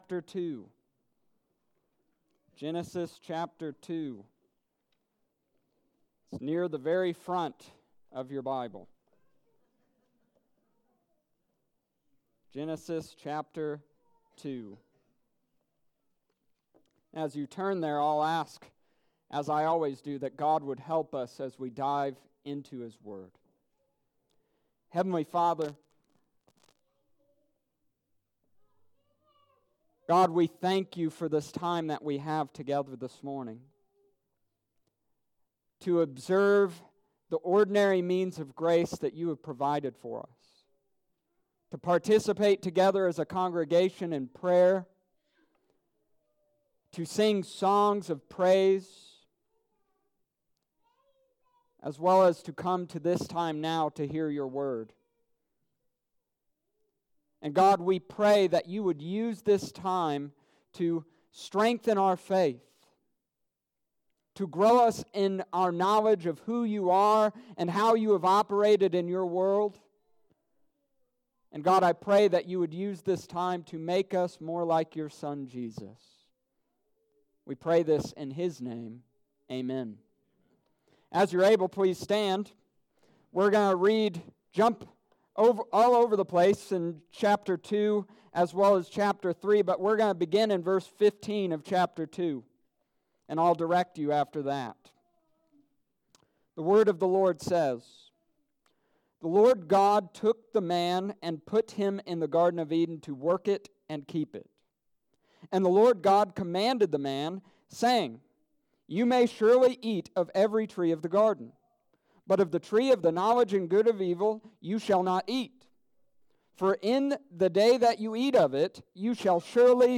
Summary of Sermon: This week began a series on the promised seed of the Old Testament we began by looking at promised serpent slaying seed.